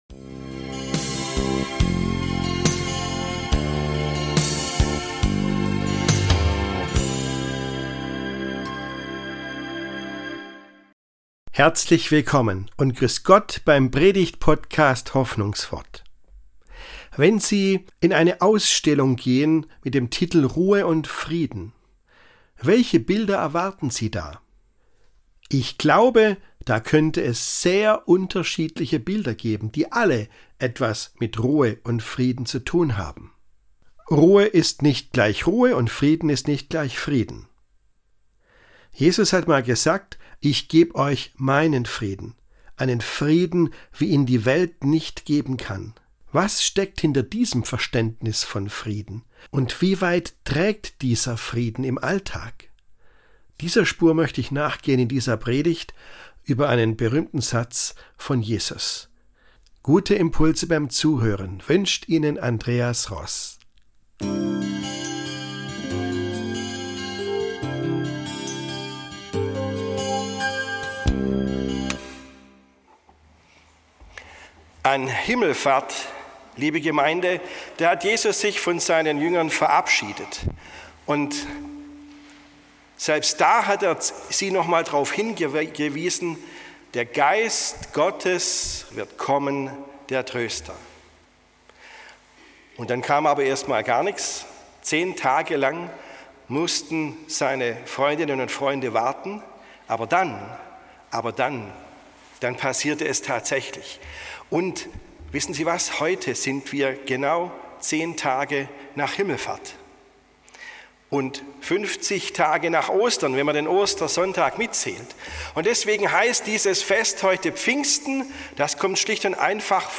Frieden in einer tosenden Welt ~ Hoffnungswort - Predigten